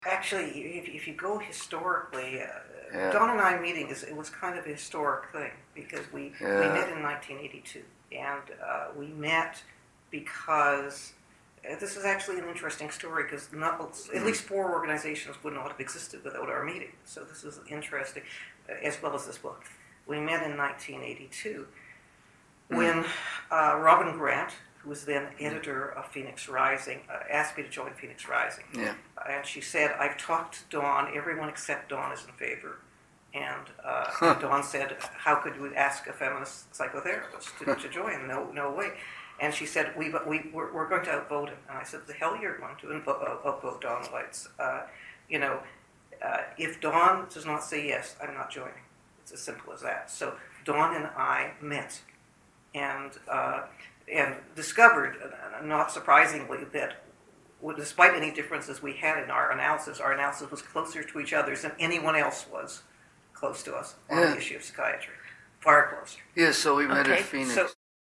at the time of their oral history interview.